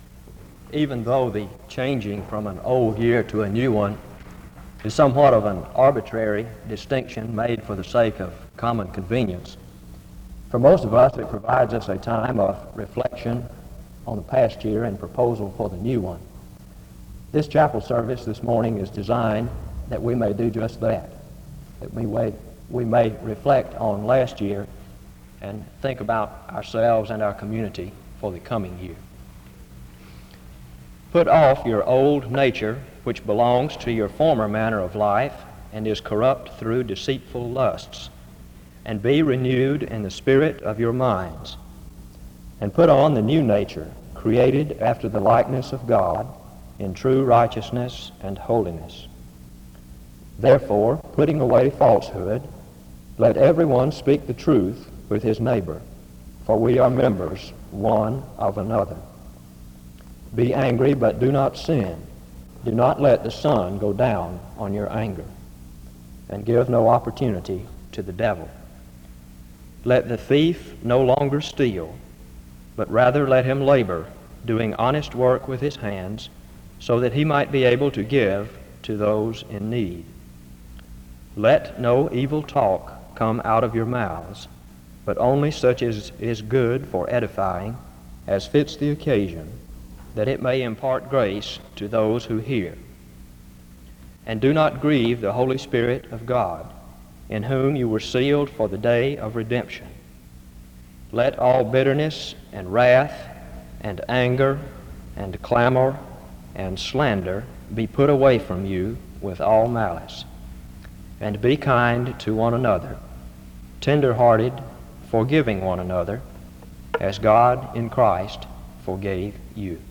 Download .mp3 Description The service opens with a scripture reading from 0:00-2:01. A responsive reading takes place from 2:13-4:16. A prayer is offered from 4:24-7:29.